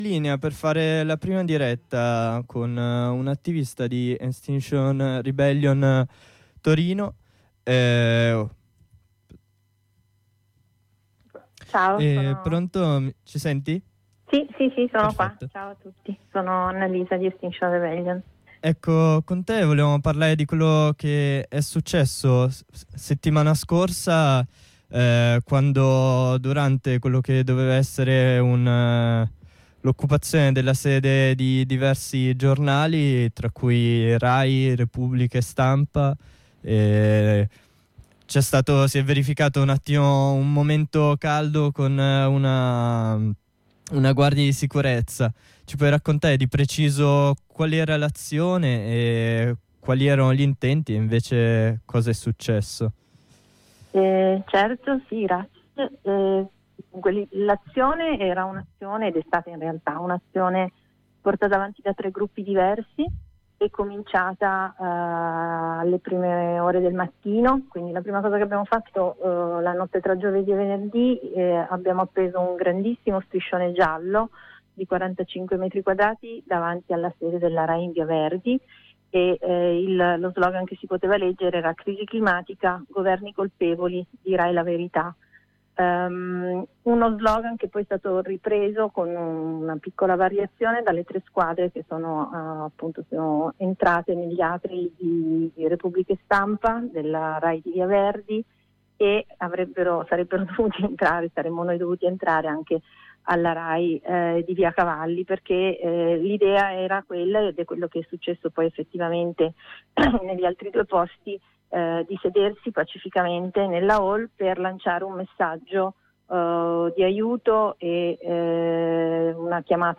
Abbiamo parlato di questo episodio e delle richieste portate avanti da XR con unx attivista di Extinction Rebellion Torino: